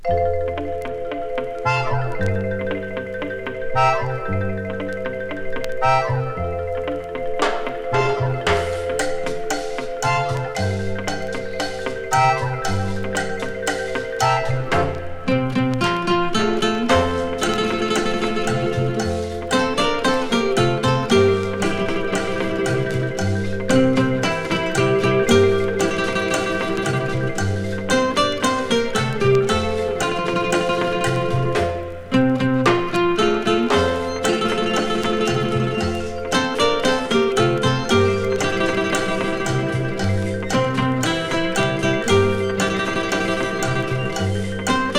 Jazz, Pop, World, Easy Listening　USA　12inchレコード　33rpm　Stereo